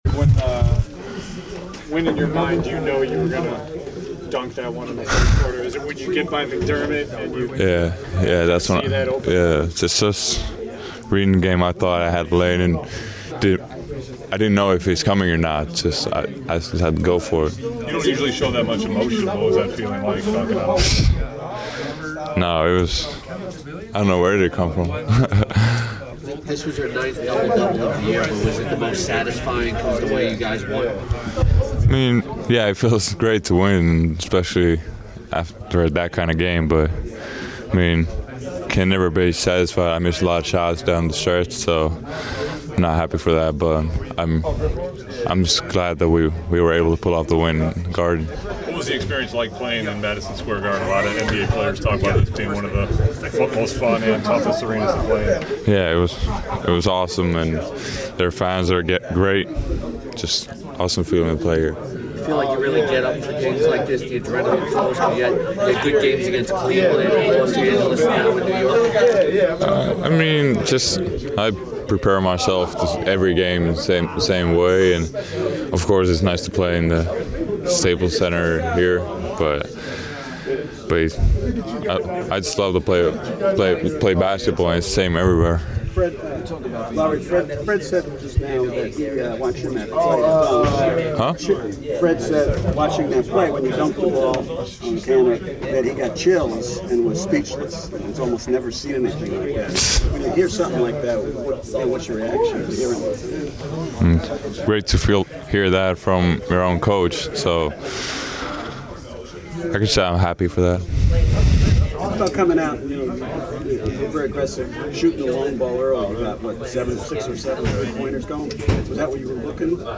The Bulls rookie talks to the assembled media,